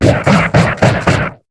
Cri de Crabominable dans Pokémon HOME.